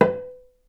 vc_pz-B4-ff.AIF